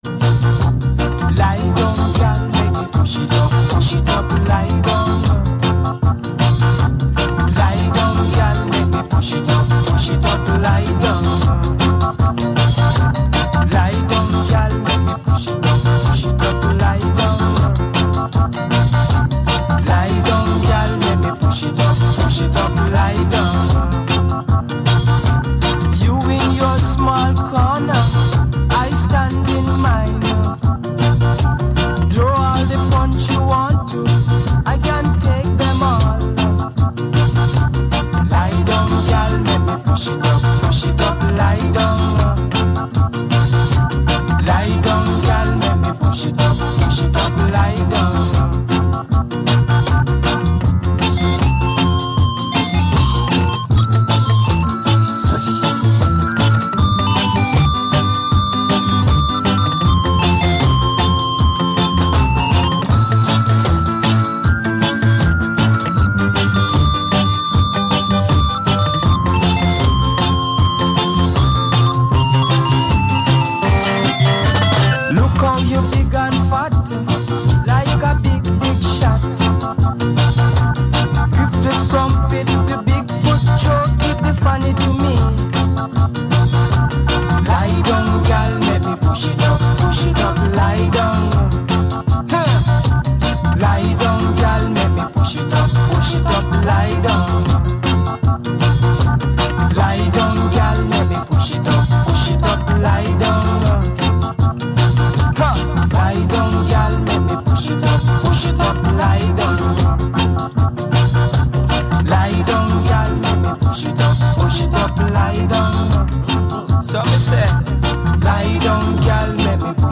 * Lion FreeCaency Radio Show *